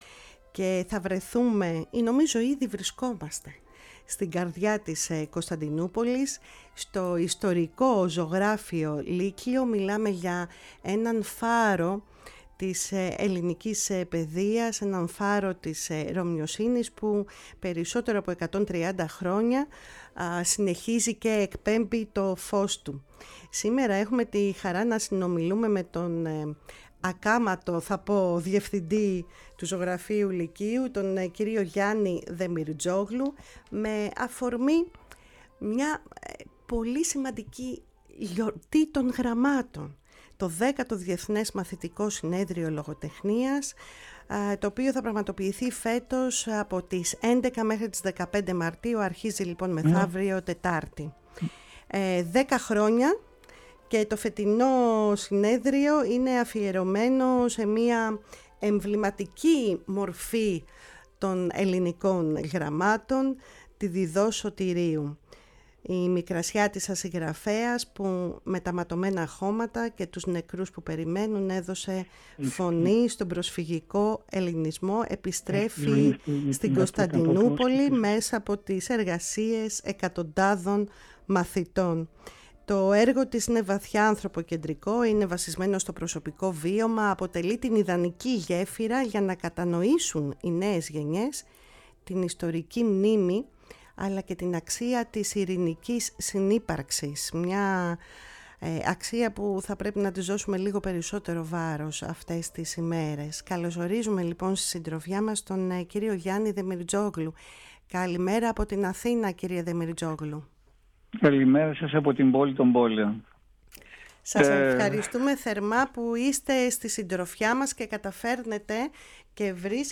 Στην εκπομπή μίλησε από την Κωνσταντινούπολη